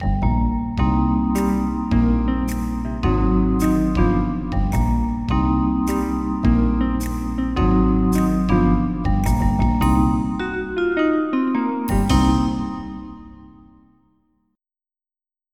MIDI Music File
Title swing=62